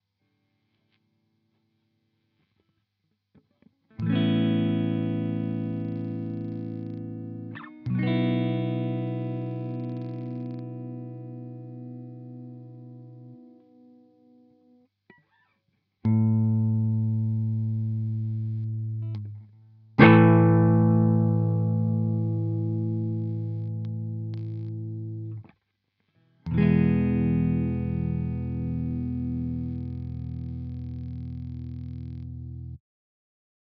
NOISE!